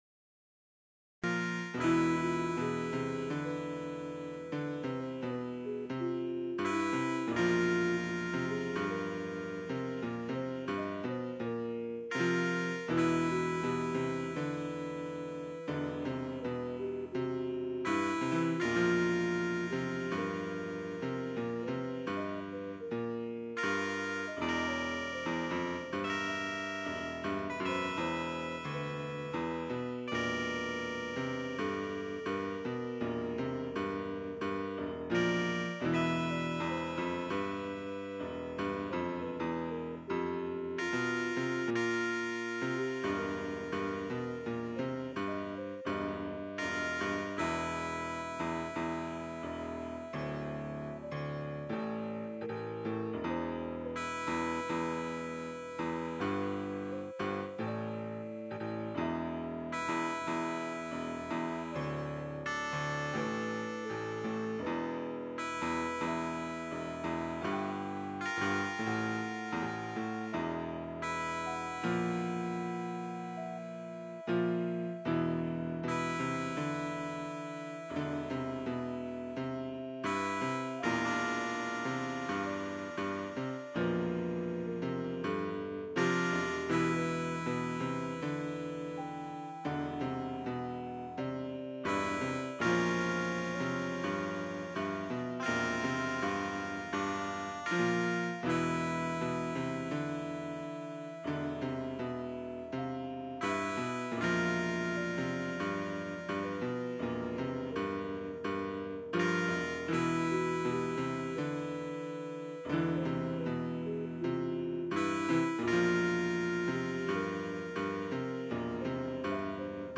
Short Bgm Music to village area